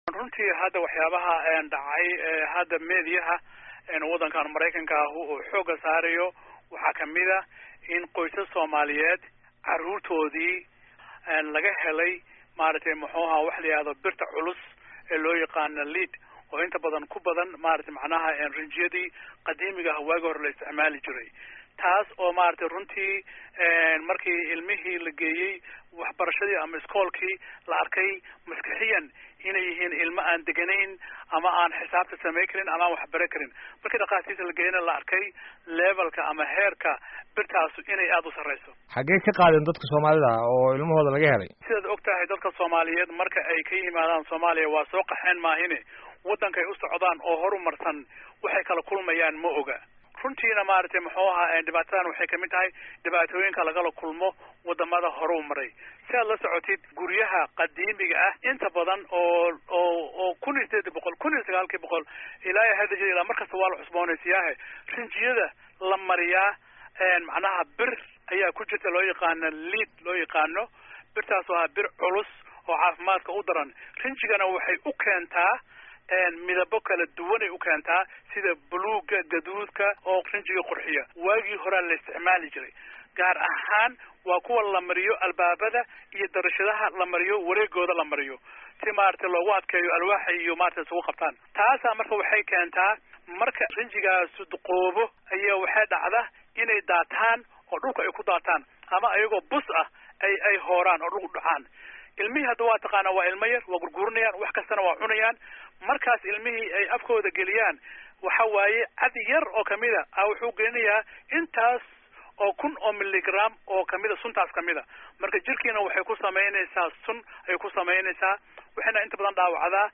Wareysi: Maine